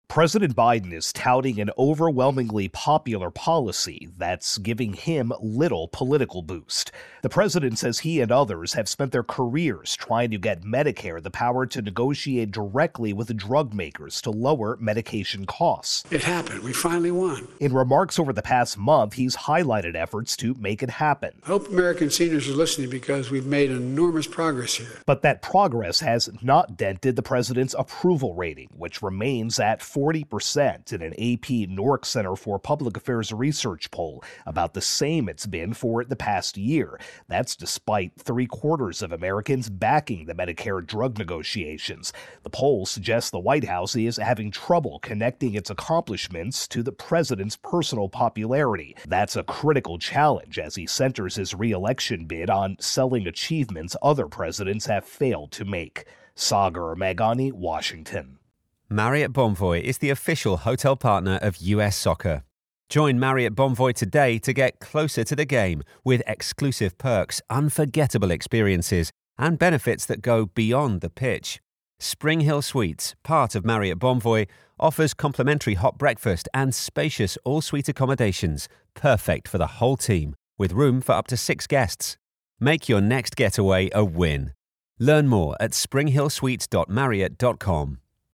reports on AP Poll-Biden.